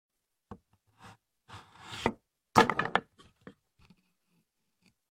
satisfying dirt block slice in sound effects free download
Watch a realistic Minecraft dirt block get sliced with perfect precision — crisp wood chips, clean cuts, pixelated texture.